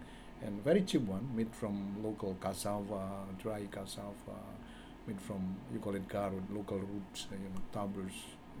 S1 = Indonesian male S2 = Malaysian female Context: S1 is discussing things he likes to eat when he is in Indonesia. S1 : ... and very cheap one made from local cassava, dry cassava (.) made from you call it garut local roots, namely tubers Intended Words: tubers Heard as: tabrus Discussion: S2 did not understand this word, so she wrote down tabrus .
Use of [ʌ] rather than [ju:] in the first syllable of tubers appears to be a spelling pronunciation by S1.